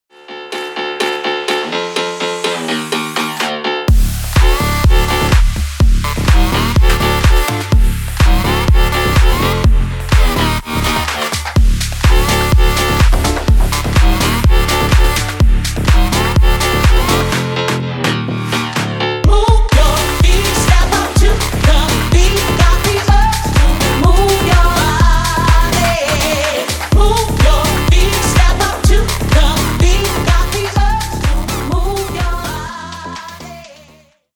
Электроника # Танцевальные
клубные